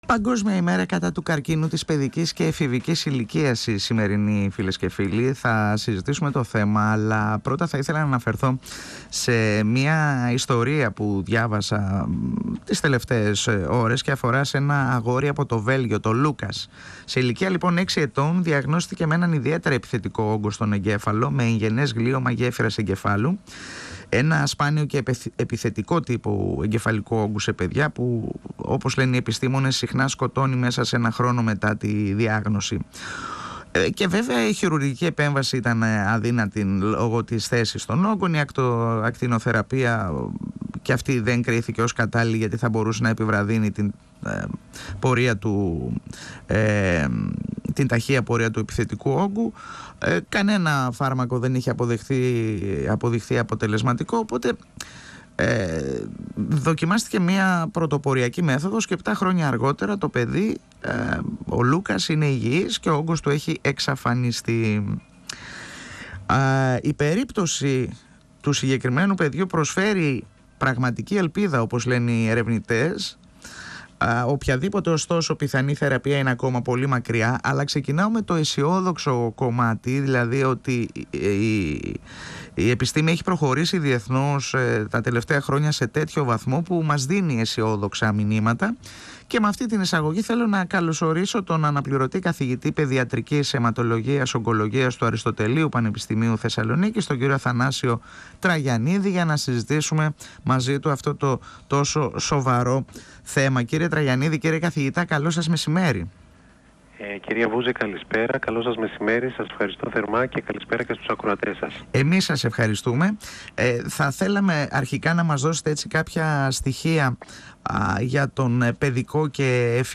Με αφορμή την Παγκόσμια Ημέρα κατά του Παιδικού Καρκίνου μίλησε στην εκπομπή «Εδώ και Τώρα» του 102FM της ΕΡΤ3  ο αναπληρωτής καθηγητής παιδιατρικής αιματολογίας